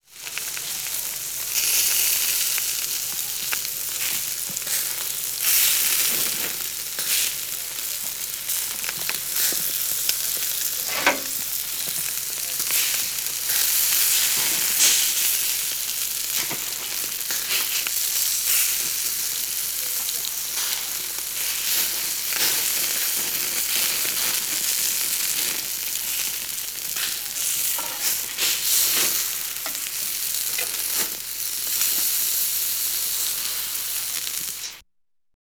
Звуки жарки, гриля
Аппетитное шипение мяса на гриле